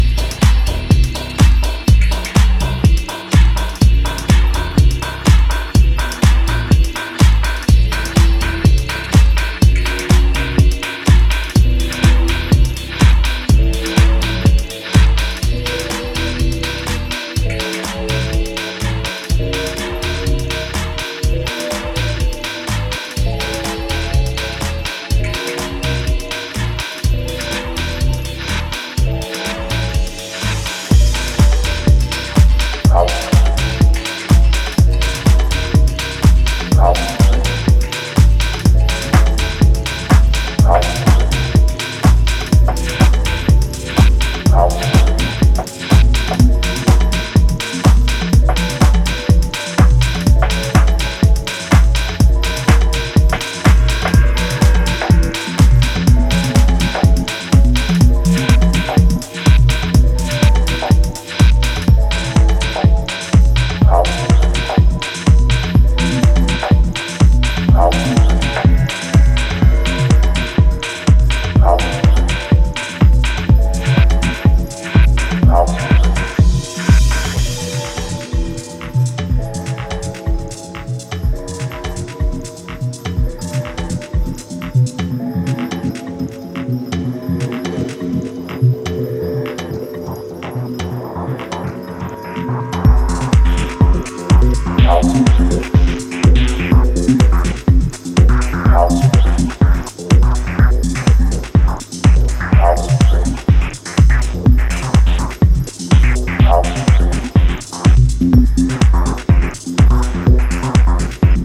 advanced dreamy electronica
deep bass tribal jams